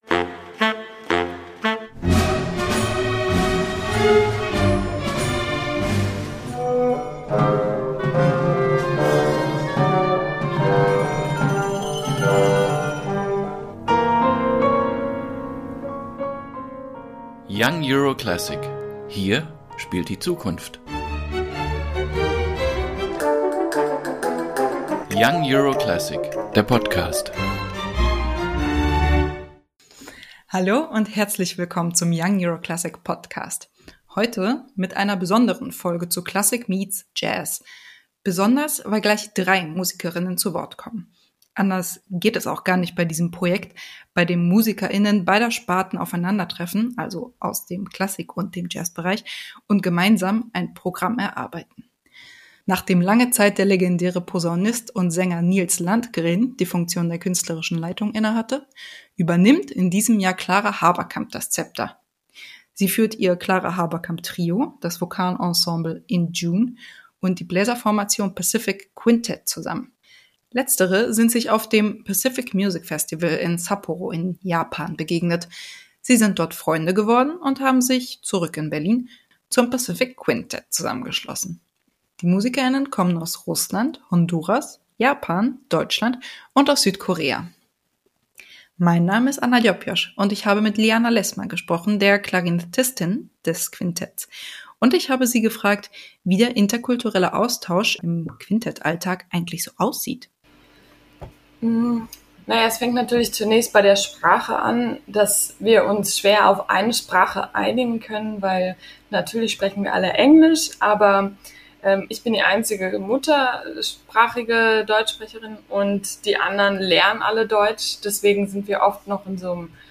Konzerteinführung
Drei Musikerinnen im Gespräch zu einem Projekt, dass von diversen Einflüssen lebt